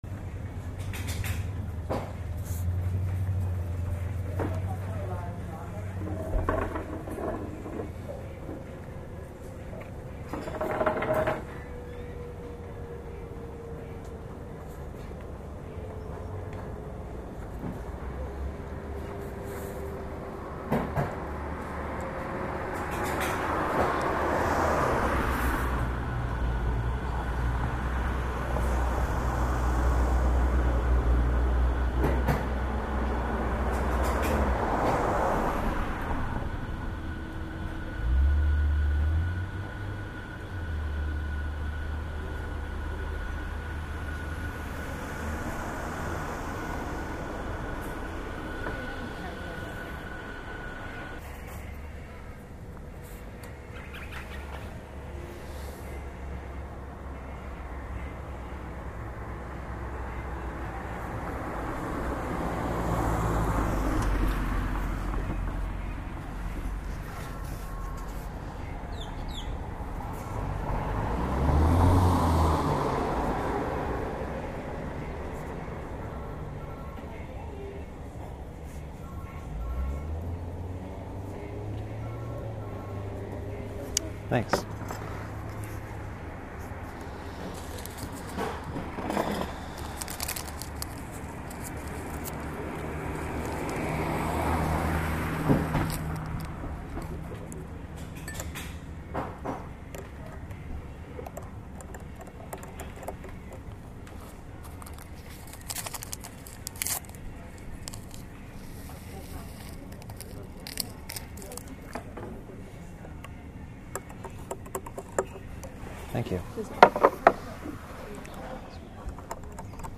I have several hours of nice field recordings on mini-disc, some of which will eventually make it up here as mp3s, but this will happen gradually.
• Sitting outside at a cafe. Cars passing by, children talking with their mother at a table next to me. September 21.
cafe-waverton.mp3